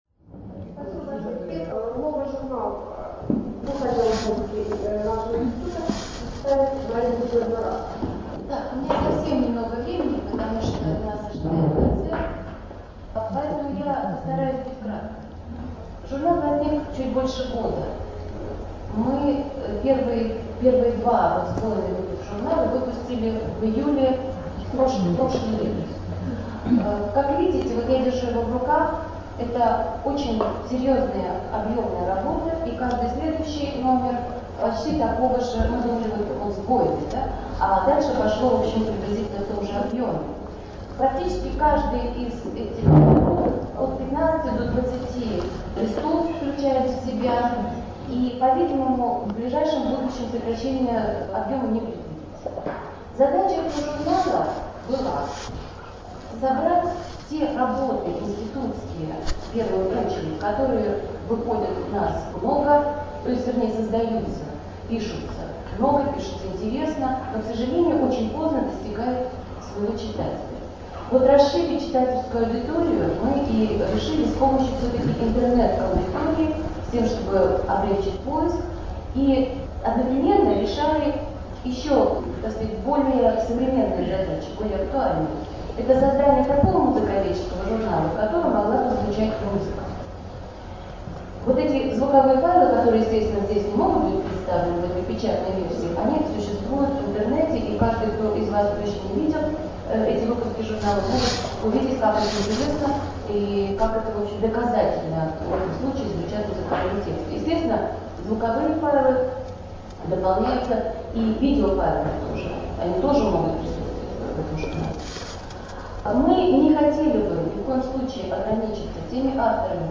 Записи с конференции «Келдышевские чтения»
Презентация изданий института: